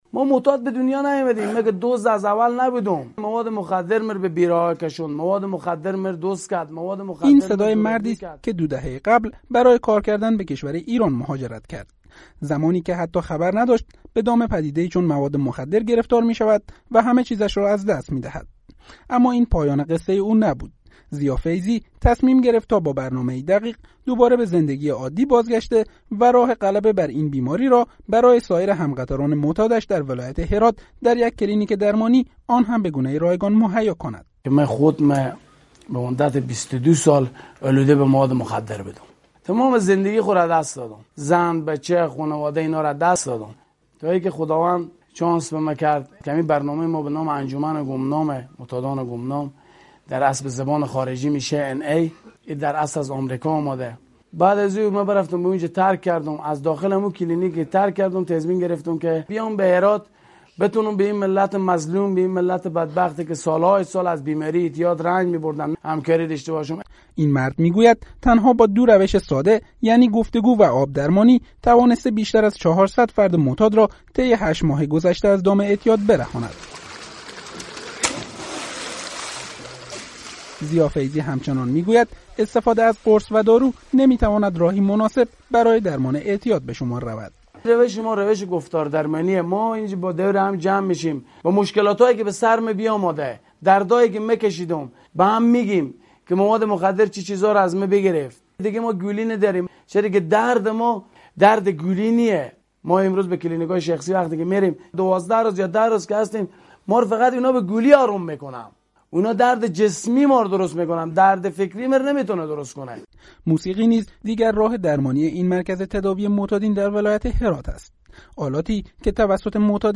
اینجا گزارش رادیویی